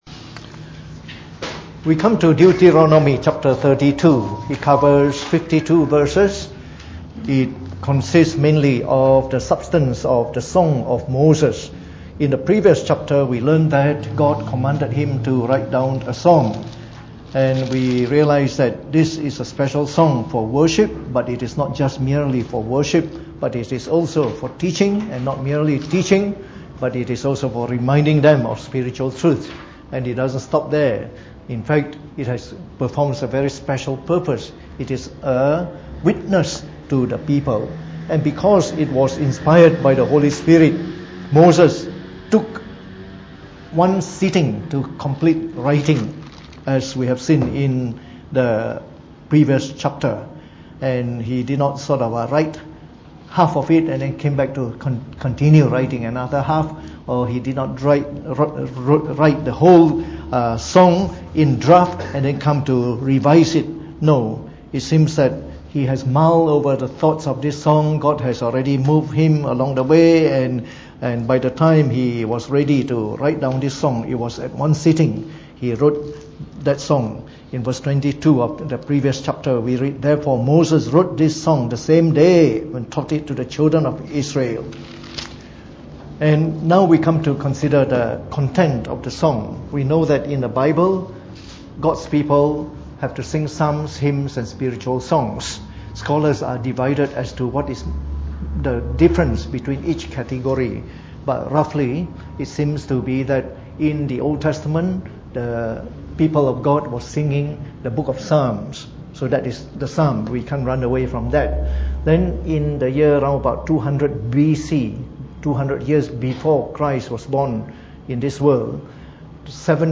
Preached on the 17th of October 2018 during the Bible Study, from our series on the book of Deuteronomy.